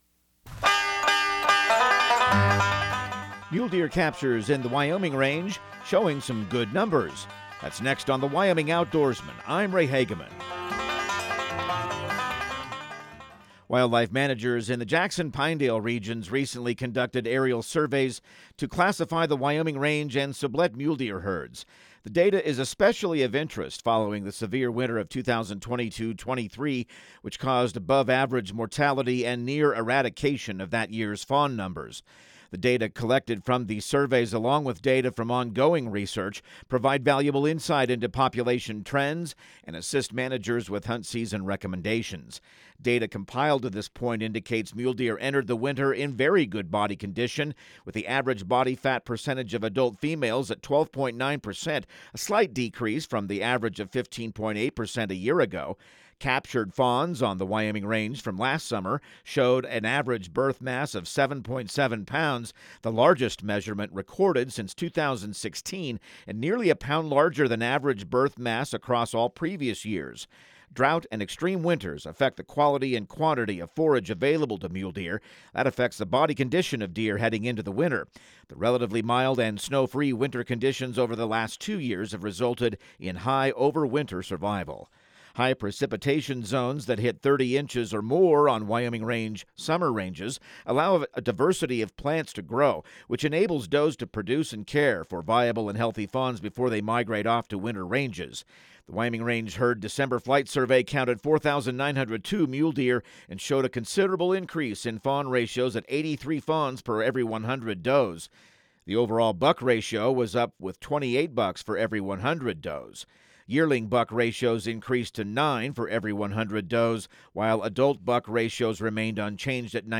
Radio news | Week of February 10